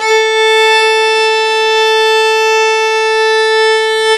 Violin-440Hz.ogg